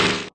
machine_4.ogg